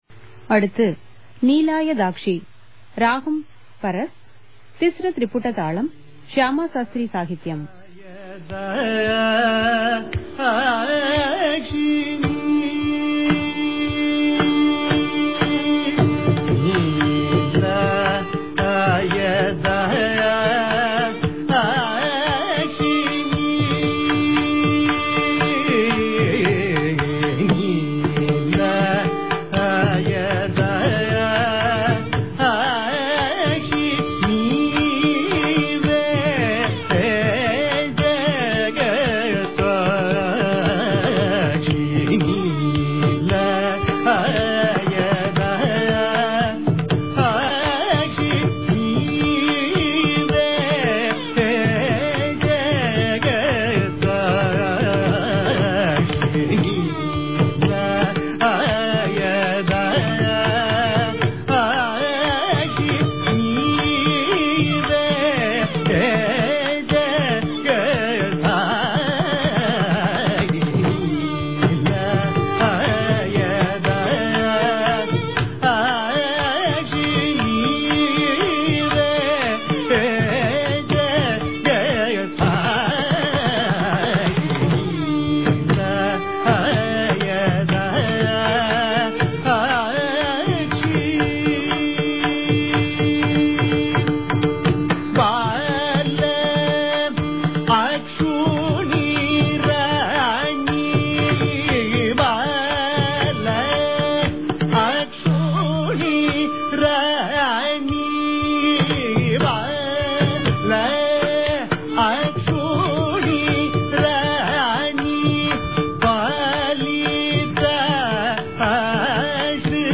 at Music Academy Violin
Mridangam